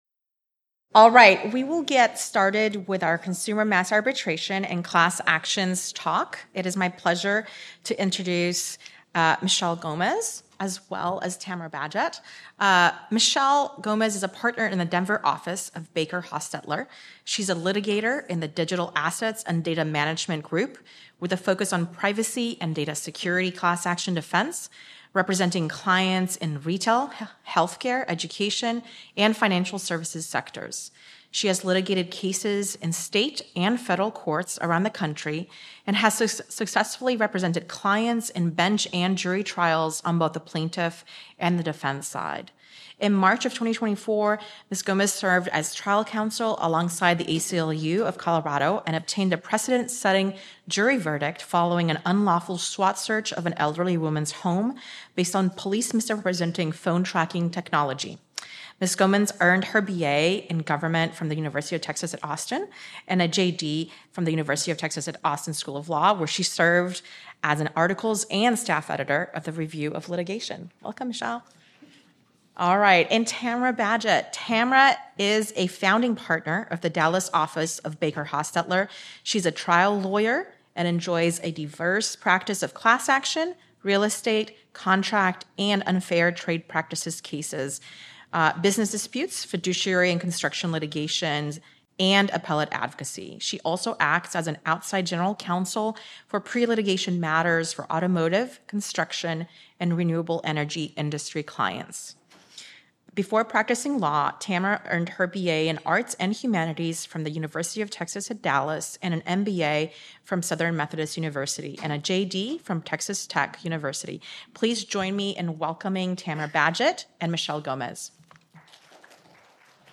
Originally presented: May 2024 Technology Law Conference